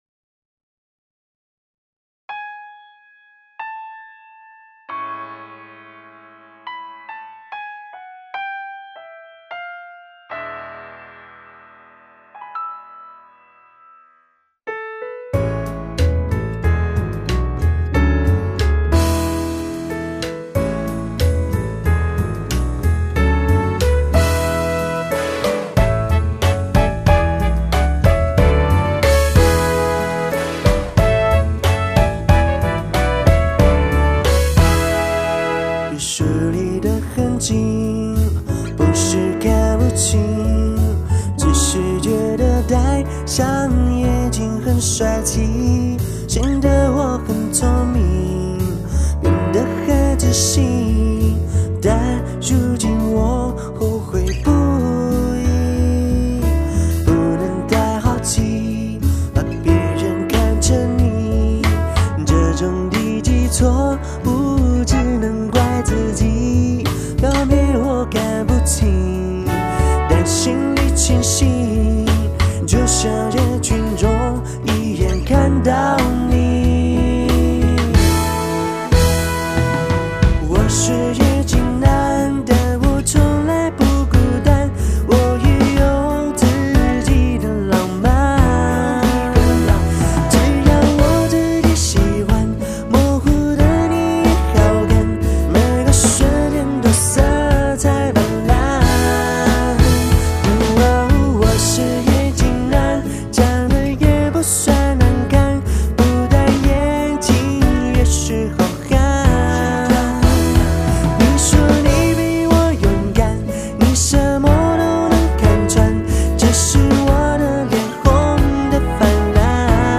柔软却不失力道
在自然流畅的旋律下，每字每句都欢快灵动，我们听到了平凡，我们更听到了自信。